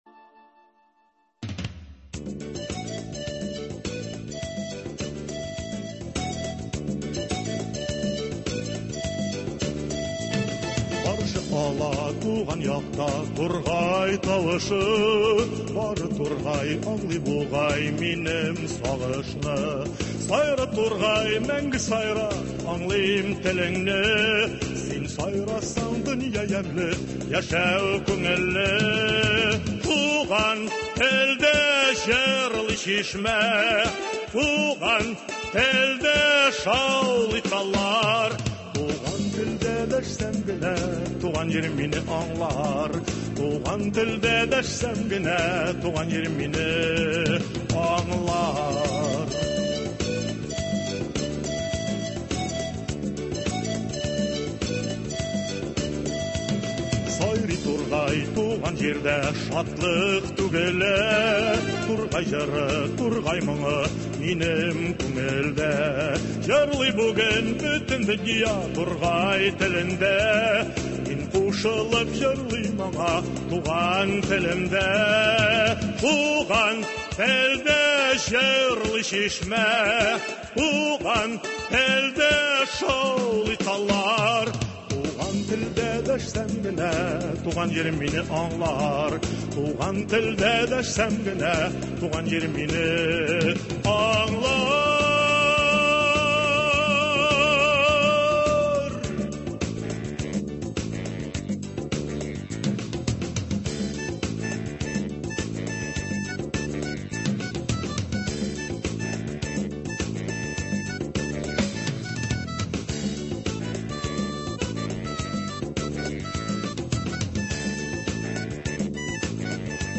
Туры эфир. 29 июль.